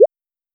recharge_capsule_4.wav